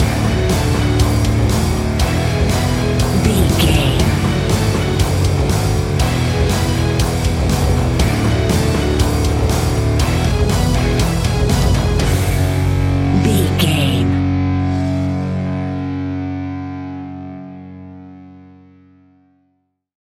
Epic / Action
Fast paced
Aeolian/Minor
F#
hard rock
guitars
heavy metal
horror rock
Heavy Metal Guitars
Metal Drums
Heavy Bass Guitars